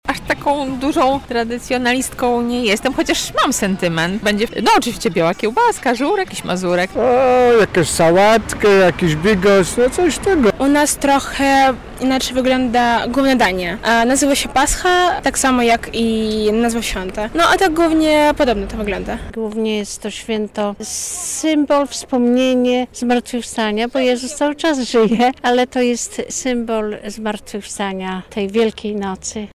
Czy lublinianie kultywują tradycje wielkanocne? [SONDA]
O świątecznych tradycjach porozmawialiśmy z mieszkańcami Lublina:
sonda